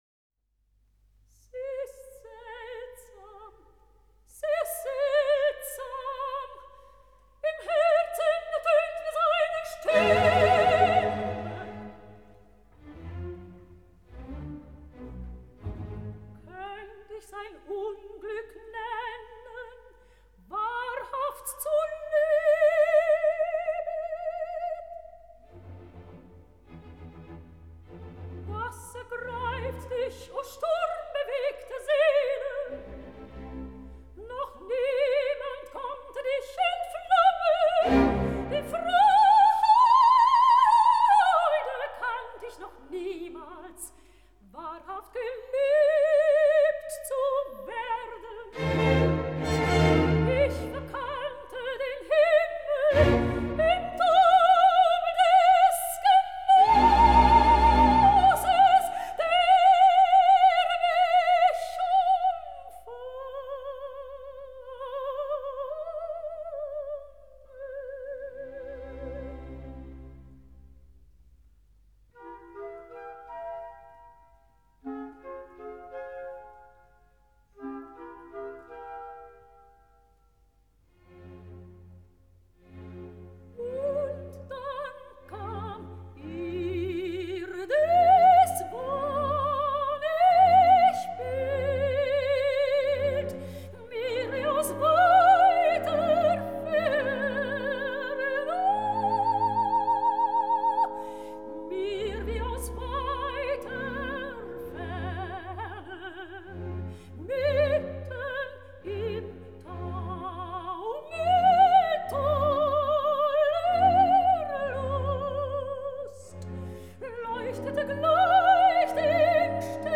Аннелизе Ротенбергер - Ария Виолетты (Дж.Верди. Травиата, 1 д.) (1970)